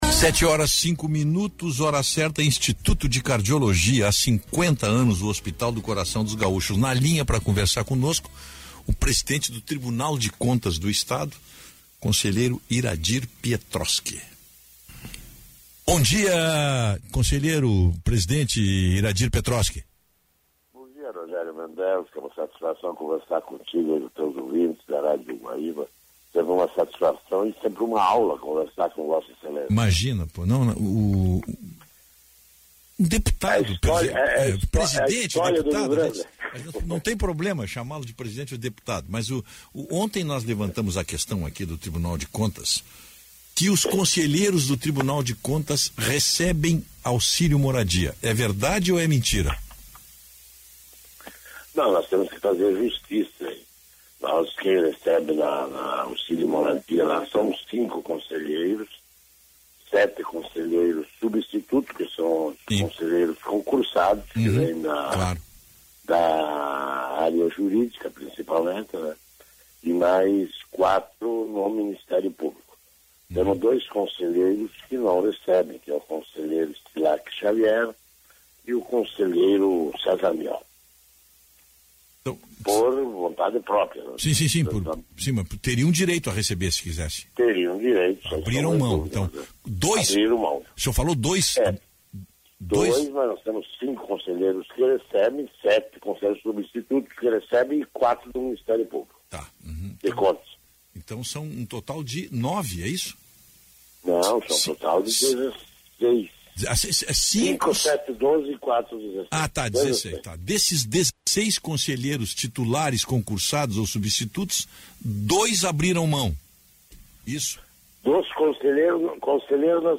Em entrevista concedida na manhã desta sexta-feira ao programa Bom Dia, da Rádio Guaíba, Pietroski afirmou que os benefícios pagos aos conselheiros são uma economia para o Estado.
Iradir-Pietroski-entrevista-Bom-Dia.mp3